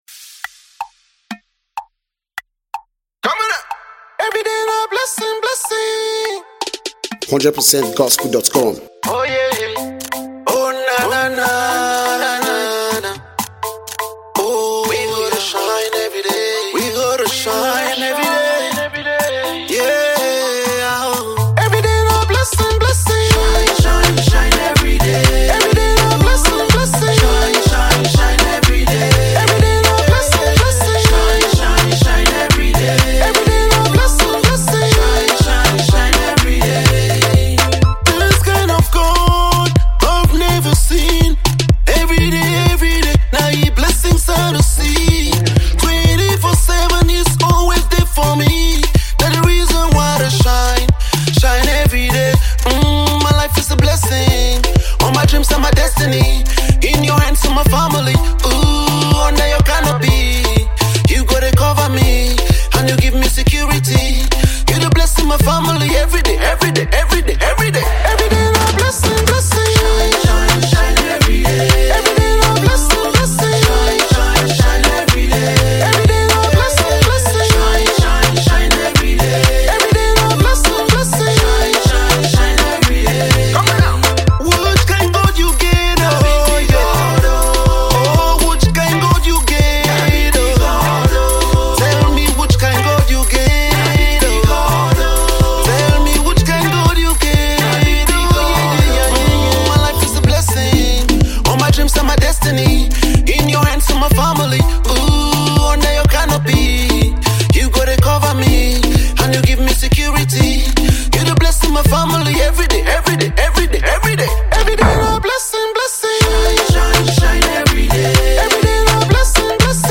jubilant and radical Afro praise style